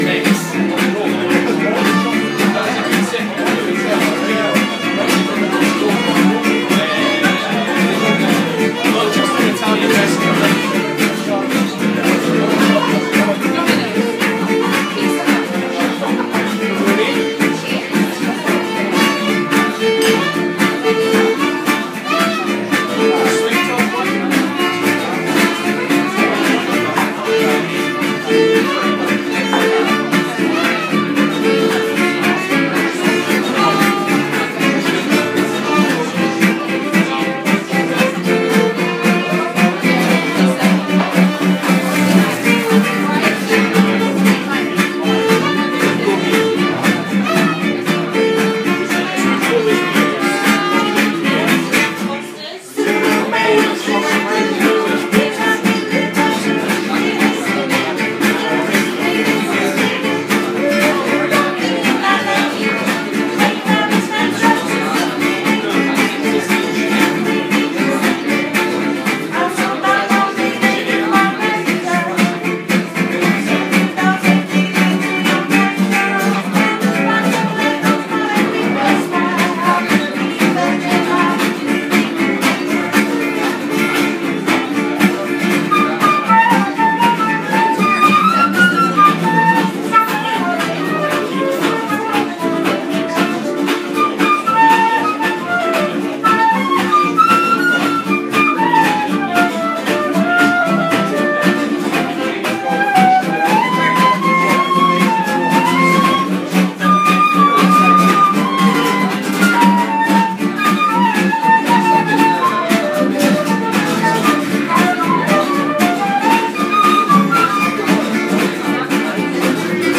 A few musicians; they join in and rotate out, with what seems like ease, familiarity, joy, and trust.
The music continued: guitar, fiddle, accordion.
I leave you with a bit of that, with the hopes that these audio files load and that you might hear a bit of what I did, all the music and chatter.
Just try to keep your foot from tapping.